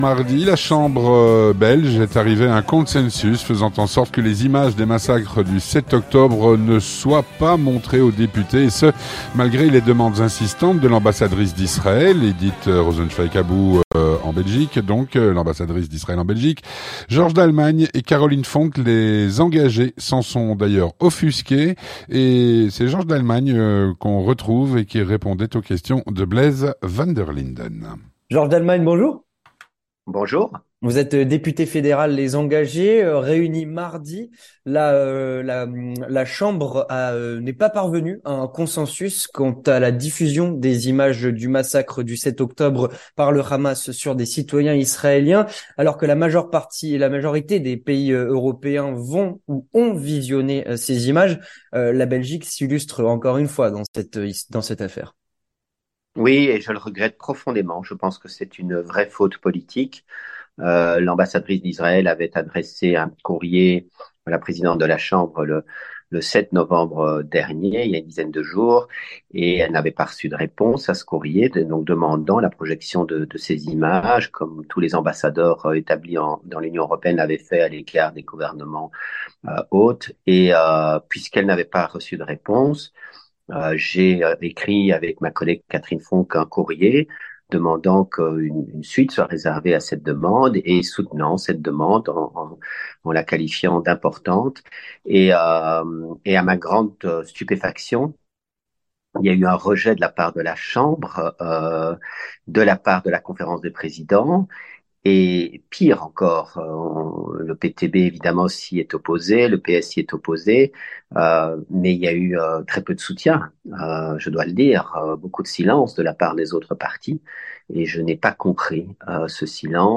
Avec Georges Dallemagne, député fédéral Les Engagés